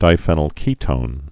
(dī-fĕnəl-kētōn, -fēnəl-)